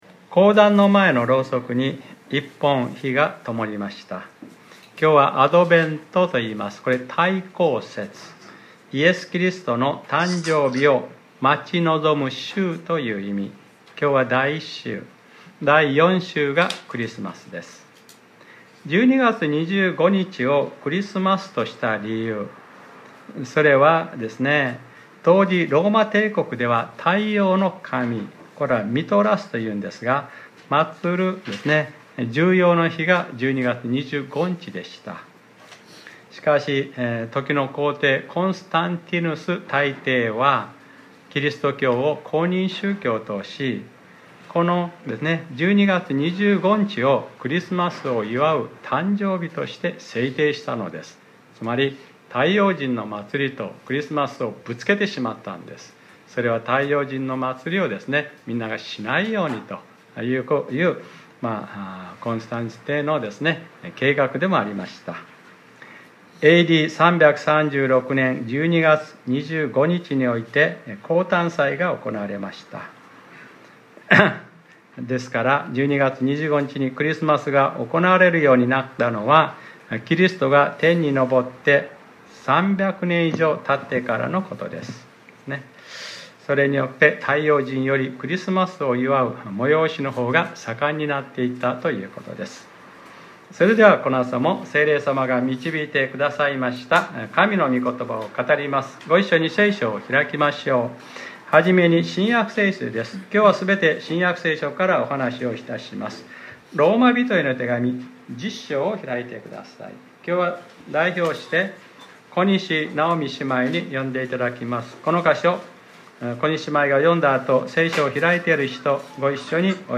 2021年11月28日（日）礼拝説教『 救いについての３つのこと 』